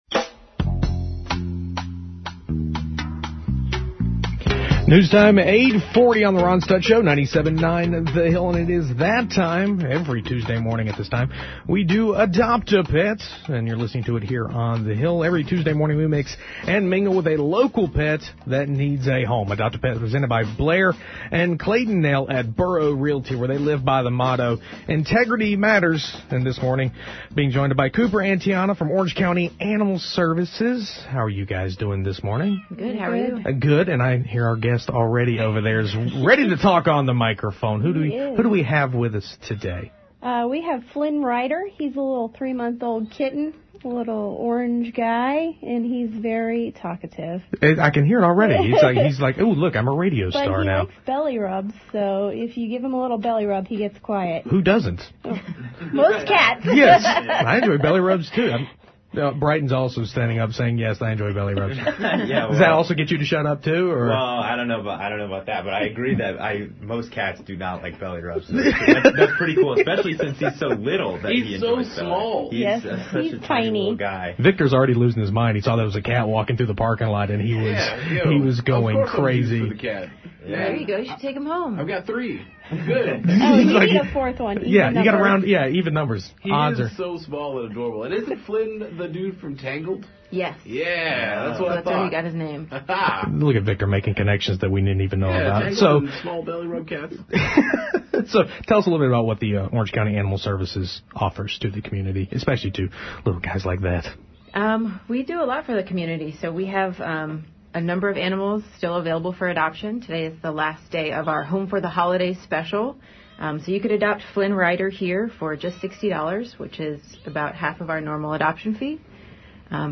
Flynn is a 15 week old male male, buff domestic shorthair and was very chatty when visiting the radio station today.
Listen to this week’s Adopt-A-Pet segment: